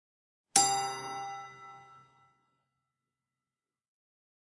Clockworth " 钟声棒 D5 (100 速度)
Tag: 祖父时钟 报时 钟声 音乐 发条 祖父 音乐音符 时钟 风铃 chime-棒